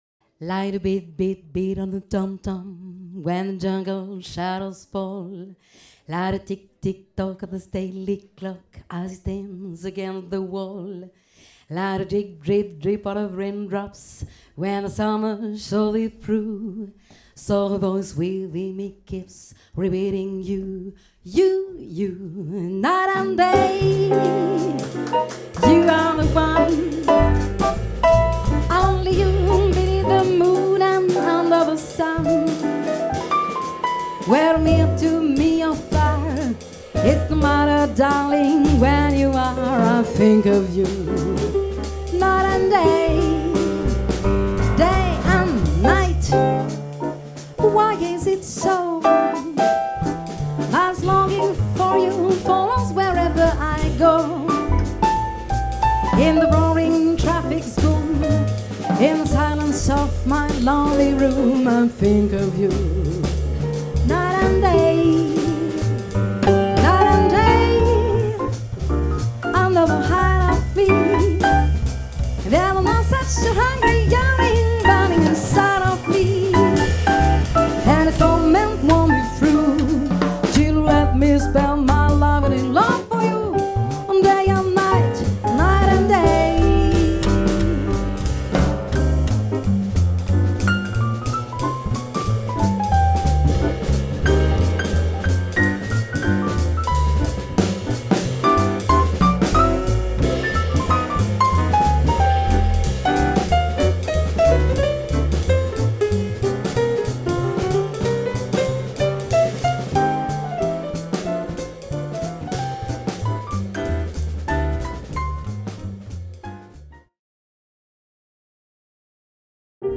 Jazz chanté